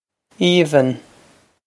Aoibheann Eev-un
Pronunciation for how to say
This is an approximate phonetic pronunciation of the phrase.